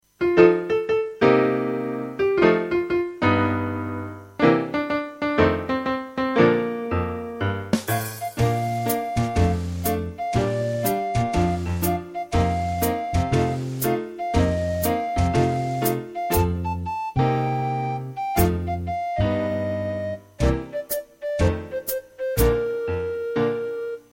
(With Melody Guide)